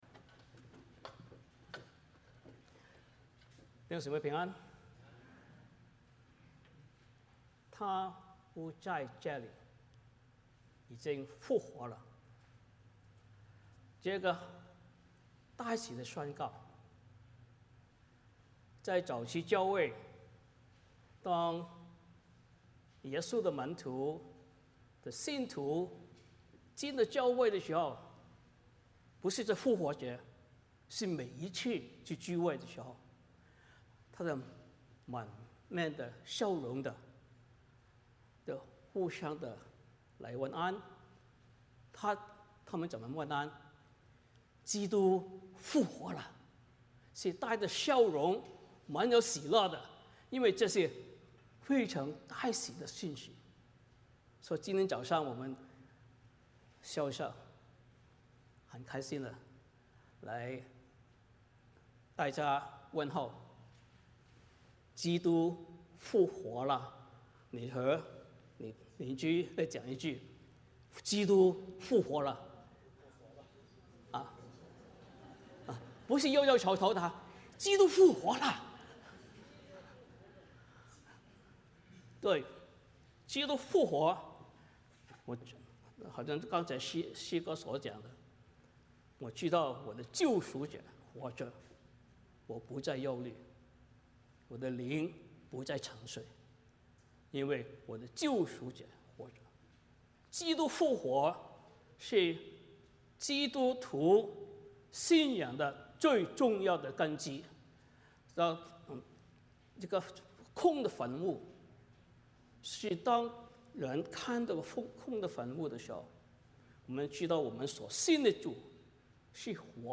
马太福音28:1-20 Service Type: 主日崇拜 欢迎大家加入我们的敬拜。